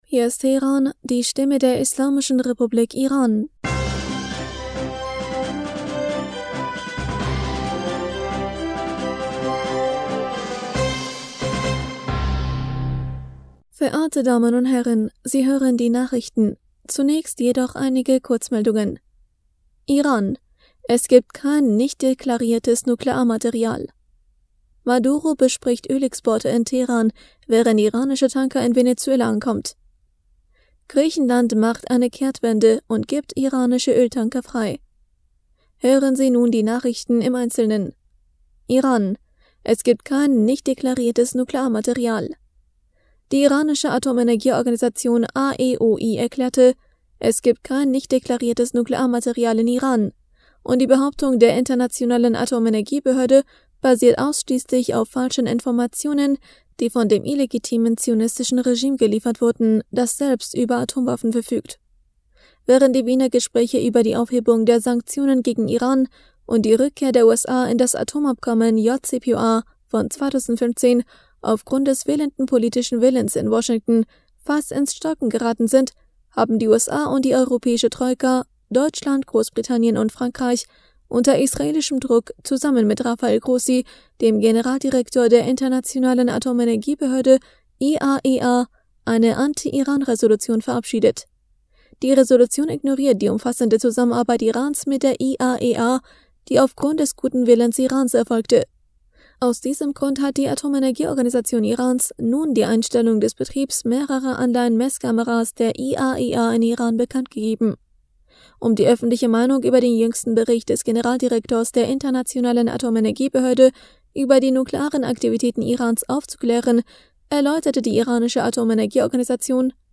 Nachrichten vom 14. Juni 2022